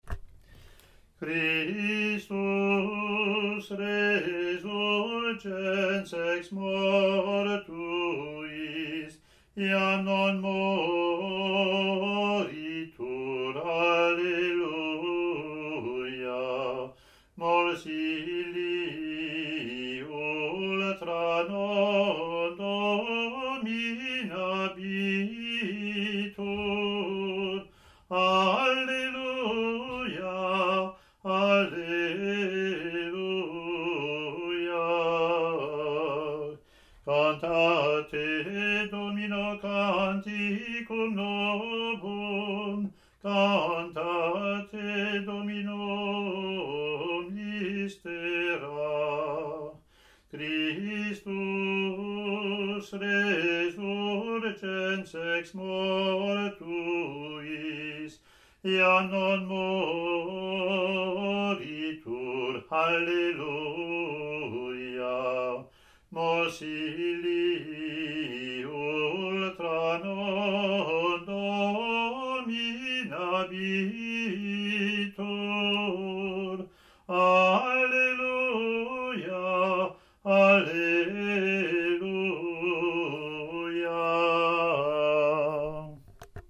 Year A Latin antiphon + verse, Years B&C Latin antiphon + verse)
ot13a-communion-gm.mp3